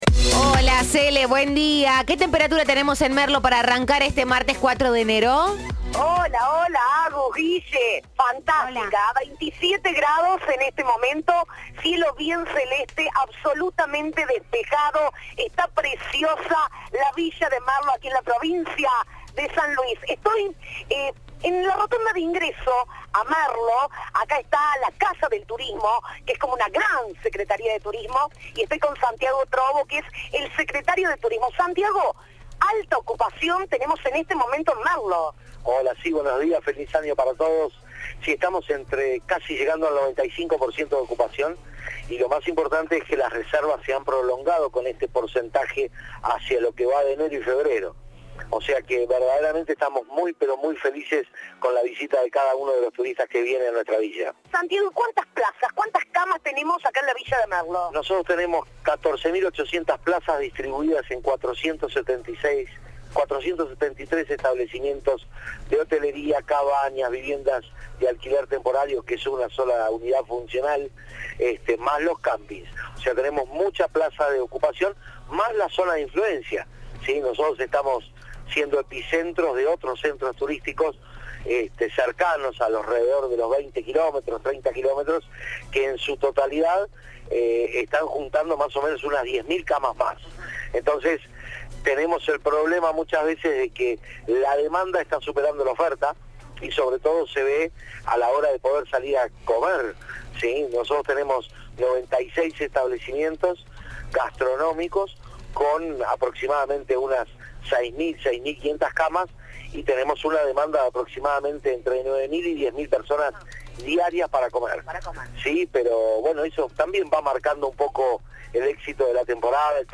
Santiago Trobo, secretario de Turismo, dijo que desde hace un año trabajan para que la ciudad venza las barreras que hay para las personas con discapacidad  motriz, auditiva o del habla.
Informe